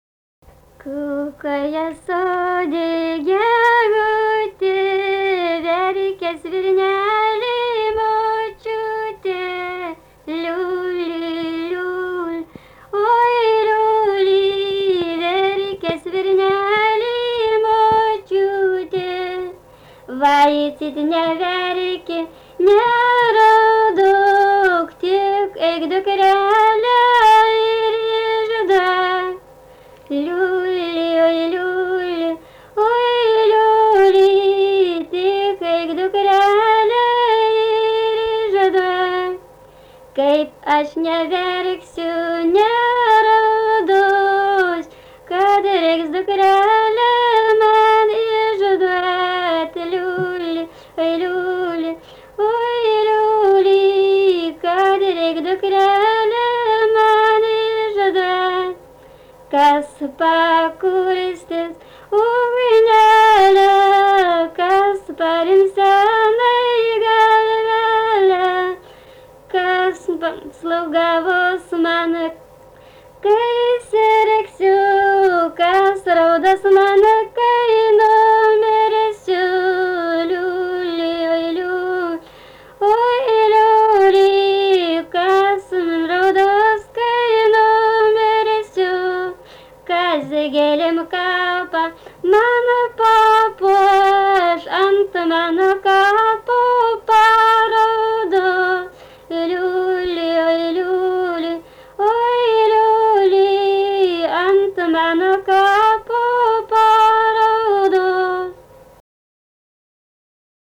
daina, karinė-istorinė
Erdvinė aprėptis Jasiuliškiai
Atlikimo pubūdis vokalinis